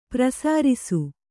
♪ prasārisu